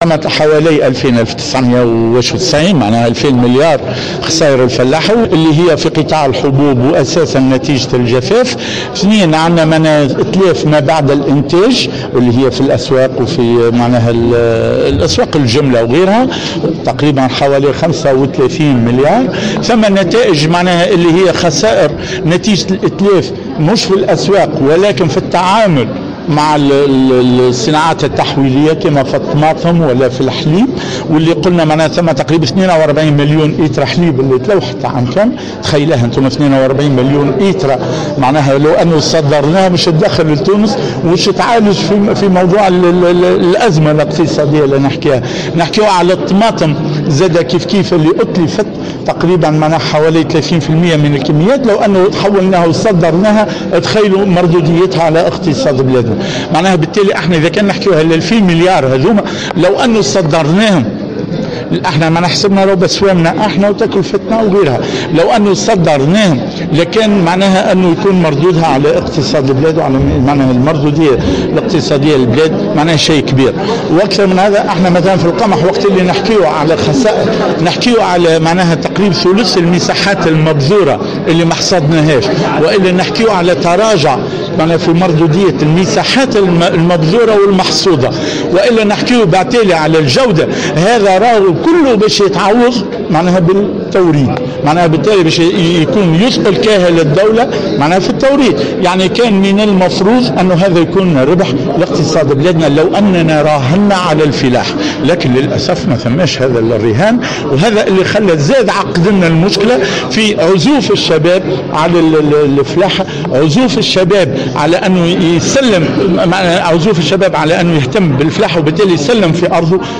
وأضاف الزار خلال ندوة صحفية انعقدت اليوم أنه يقع سنويا إتلاف كميات كبيرة من المنتوجات الفلاحية بما قيمته حوالي 35 مليار، من ذلك إتلاف 42 مليون لتر من الحليب وحوالي 30 بالمائة من كميات الطماطم التي تم إنتاجها.